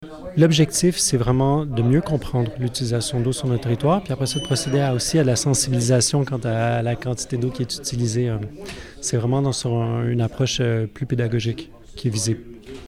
a expliqué le but visé par ce projet lors de la séance municipale lundi.